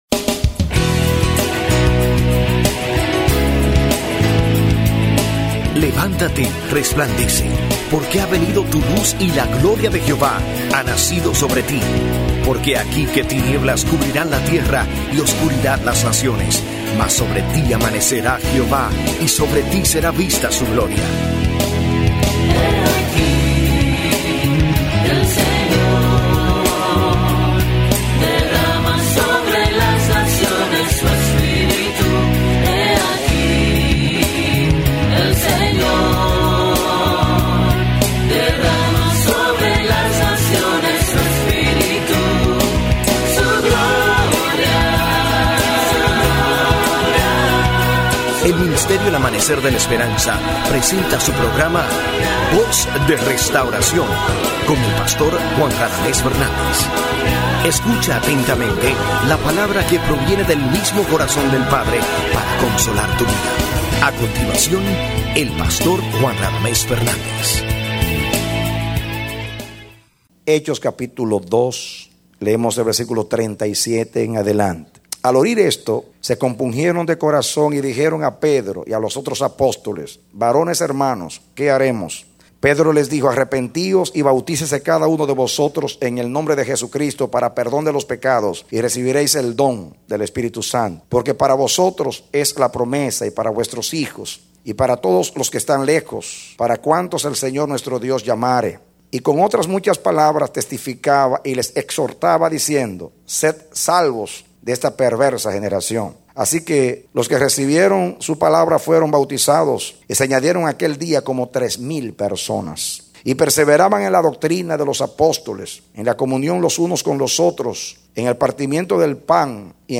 Mensaje: “La Iglesia Saludable # 1”